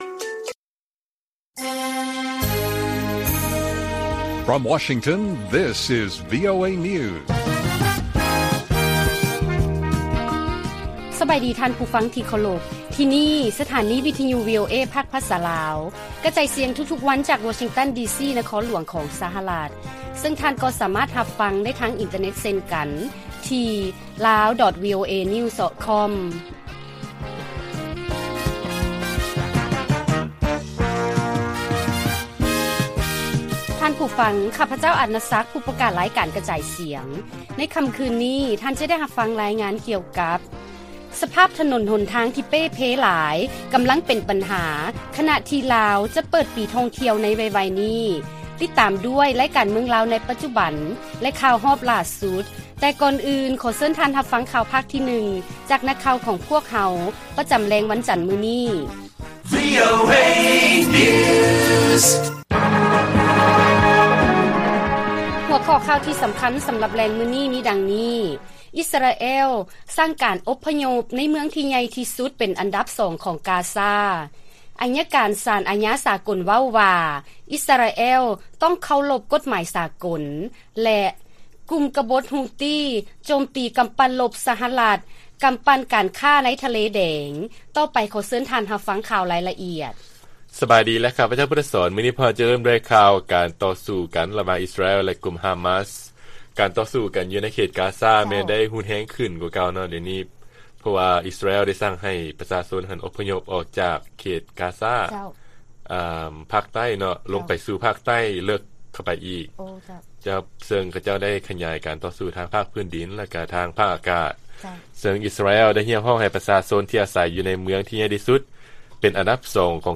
ລາຍການກະຈາຍສຽງຂອງວີໂອເອ ລາວ: ອິສຣາແອລ ສັ່ງການອົບພະຍົບໃນເມືອງທີ່ໃຫຍ່ທີ່ສຸດເປັນອັນດັບສອງຂອງ ກາຊາ